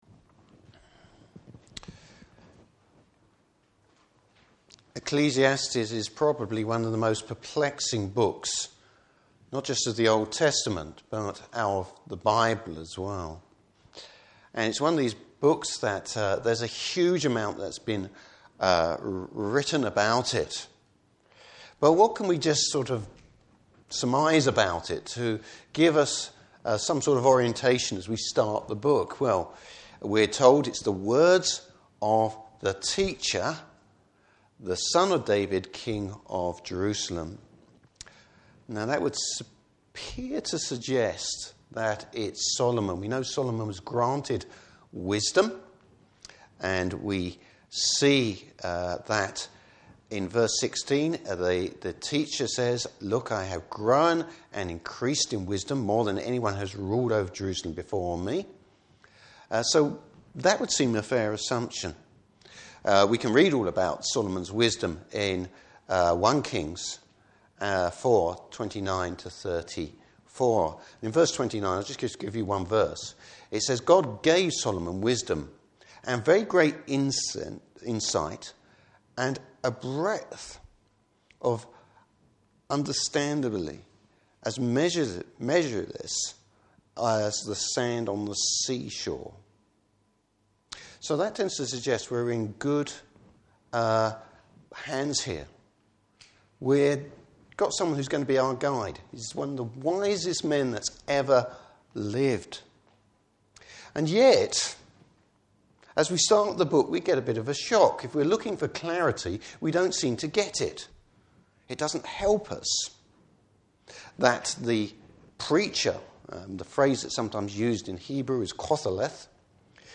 Service Type: Morning Service Bible Text: Ecclesiastes 1.